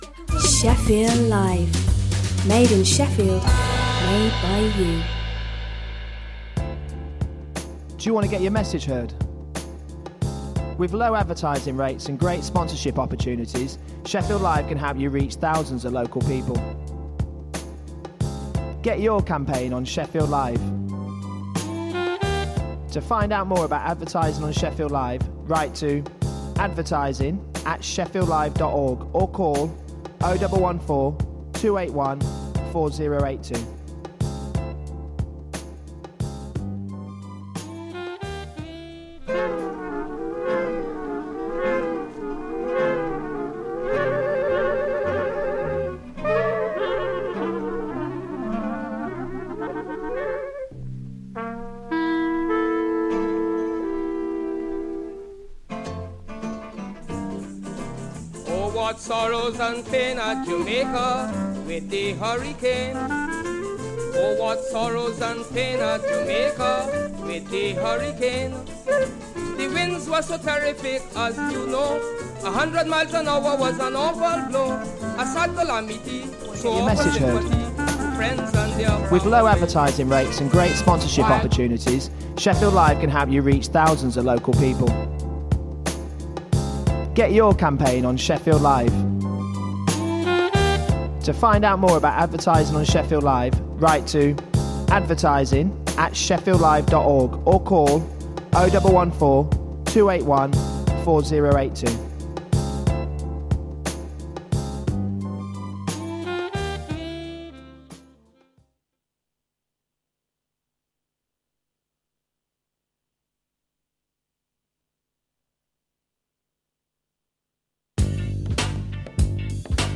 Magazine programme for the Yemeni community